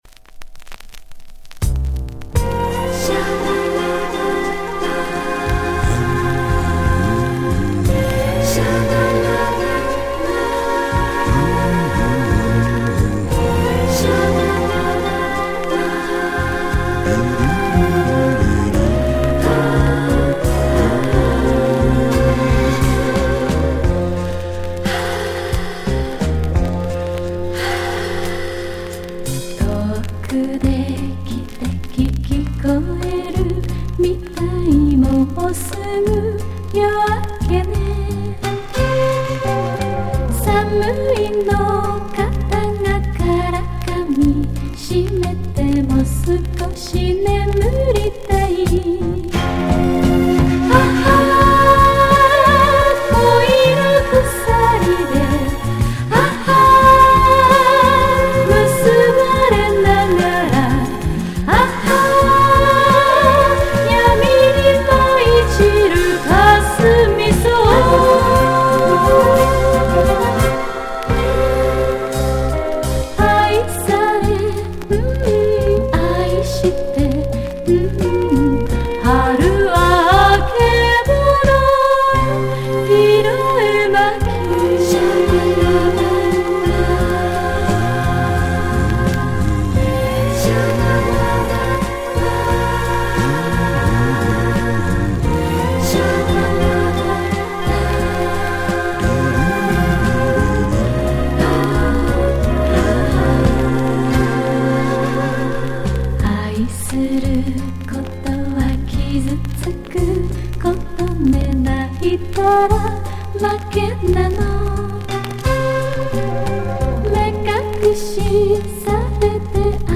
Запись была сделана с винила.